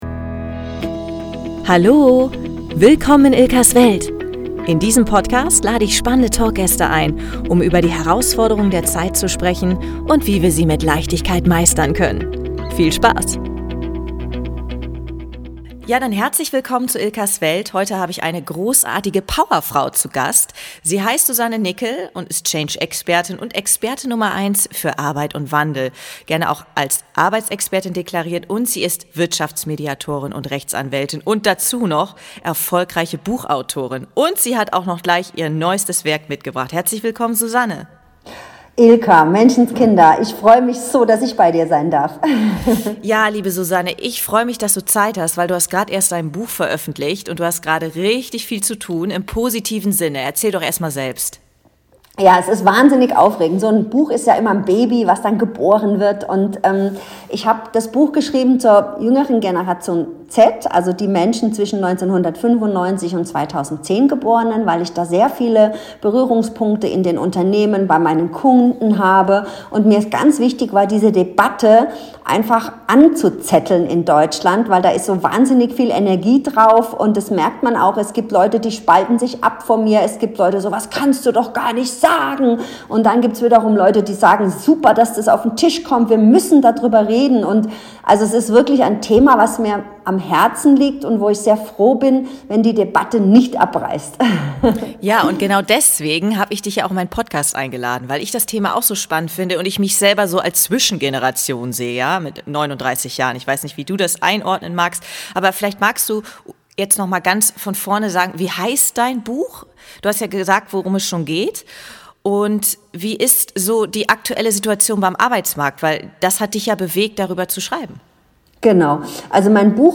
Die Beiden sprechen über die Generation Z, die die Arbeitswelt auf den Kopf stellt und uns zum Handeln zwingt. Warum hat diese Generation ihre Ansprüche? Und welche Bedeutung hat dies für die Wirtschaft und die Arbeitswelt?